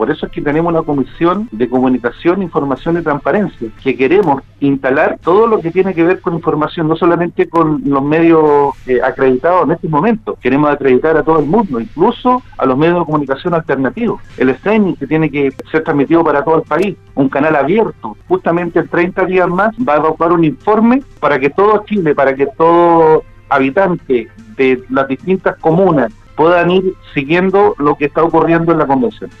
Sin embargo, el constituyente socialista por el Distrito 25, Mario Vargas, afirmó en entrevista con Radio Sago que son los miembros del conglomerado Vamos por Chile los que han querido instalar una visión de poco trabajo del organismo.